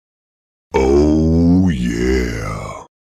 oh-yeah-sound-effect_jr83Ulx.mp3